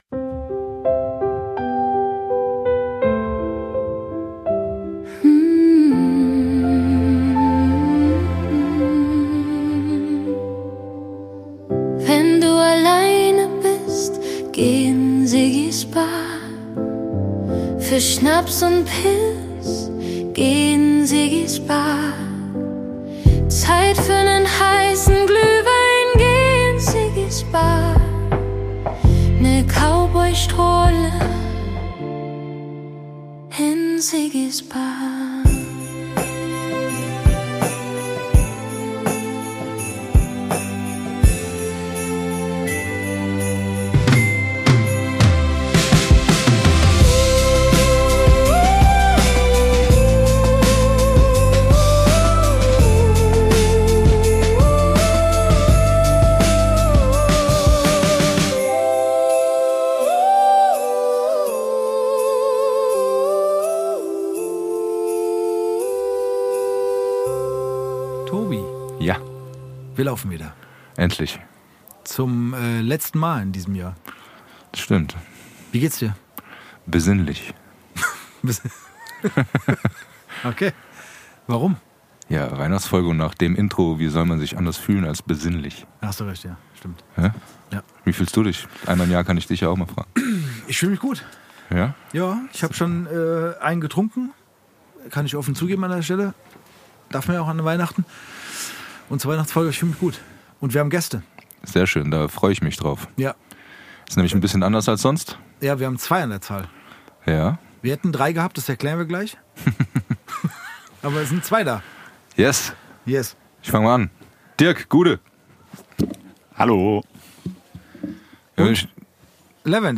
Dieses Jahr sind zwei weitere Herren mit dabei.